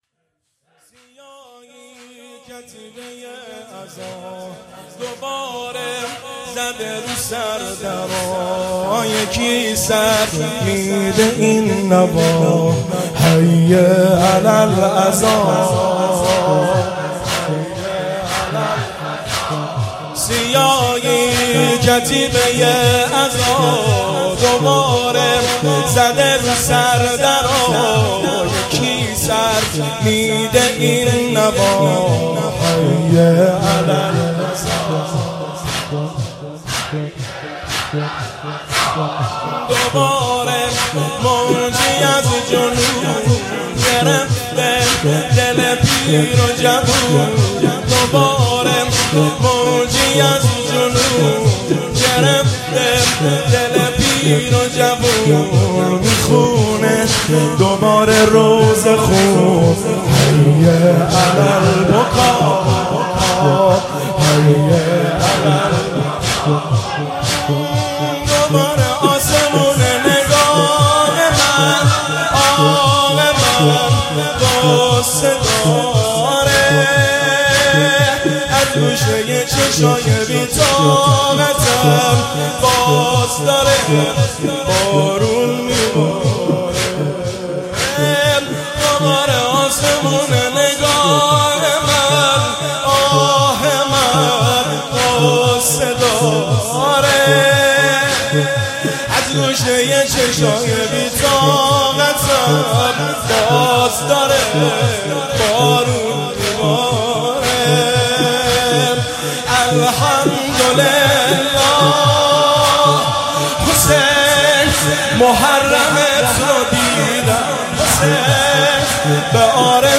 چاووش محرم